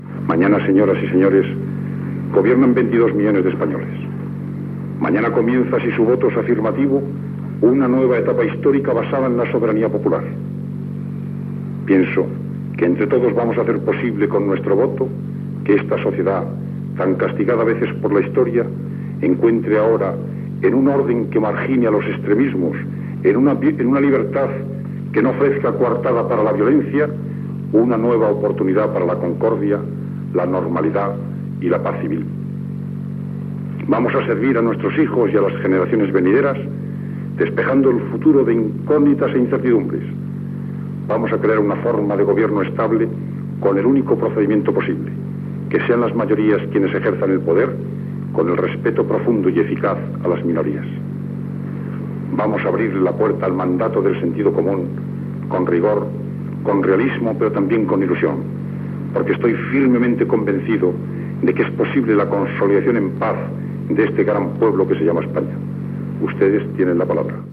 Discurs del president del govern espanyol Adolfo Suárez el dia abans del referèndum sobre la Llei per a la Reforma Política
Informatiu
Extret del programa "El sonido de la historia", emès per Radio 5 Todo Noticias el 22 de desembre de 2012